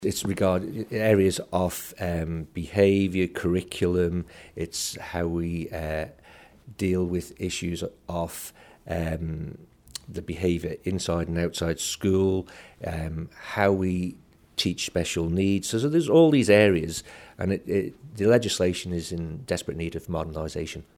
DEC Minister Graham Cregeen says a draft Bill will go out for public consultation over the summer and Tynwald will debate it early in 2018: